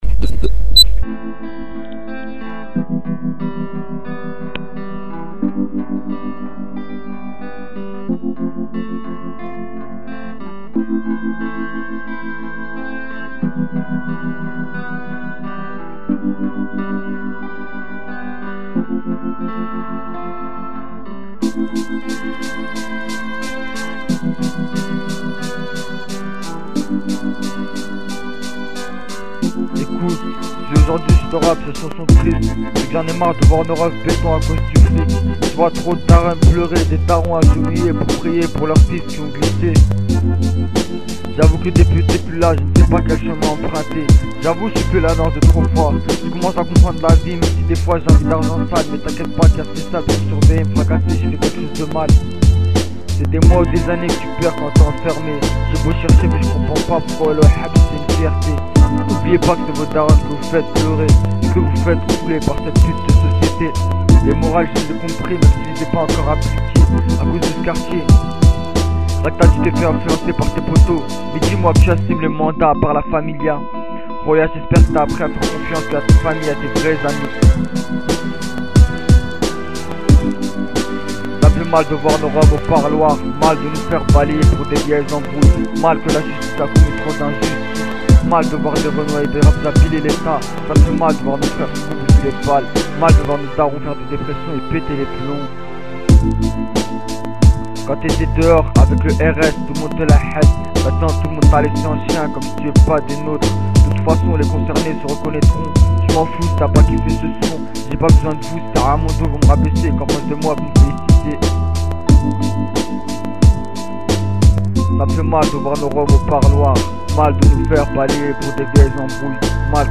rapeur du 92